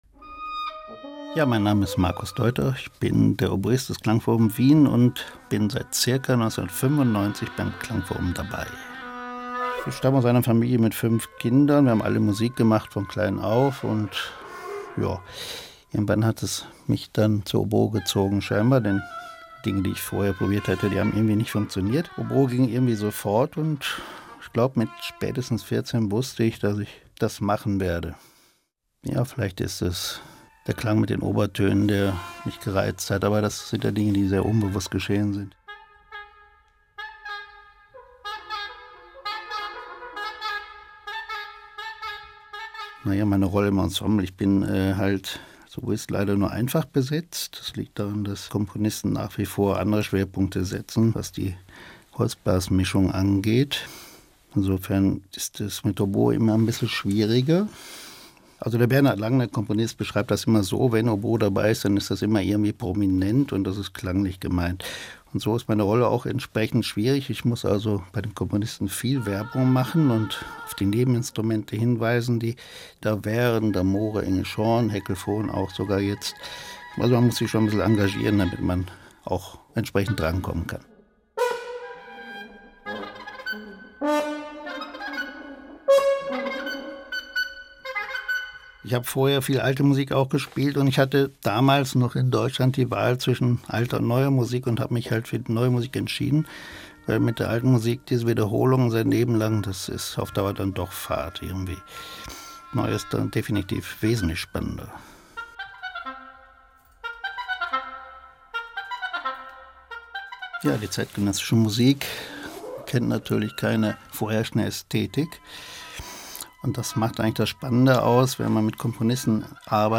Ö1 Audio-Porträt https